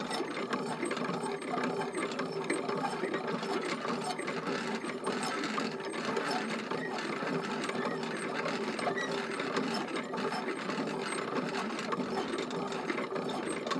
pgs/Assets/Audio/Sci-Fi Sounds/Mechanical/Engine 2 Loop.wav at 7452e70b8c5ad2f7daae623e1a952eb18c9caab4
Engine 2 Loop.wav